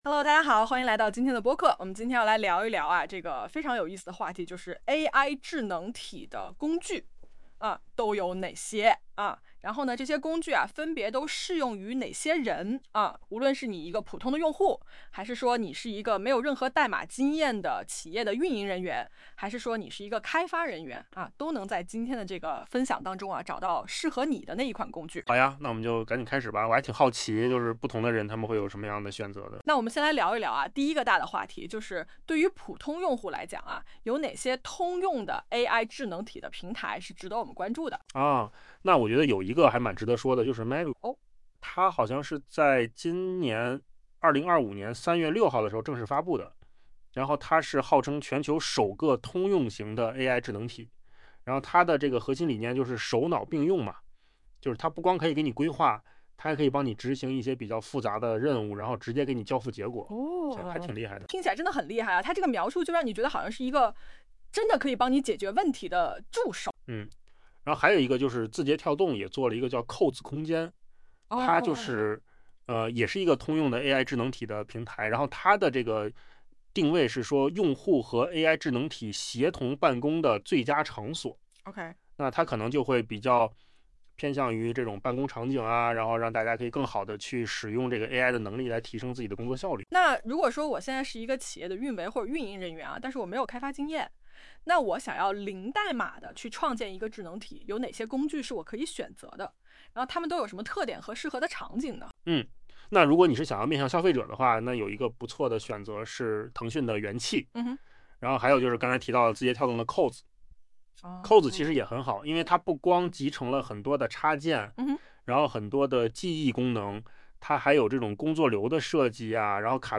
用扣子空间生成的
本文播客音频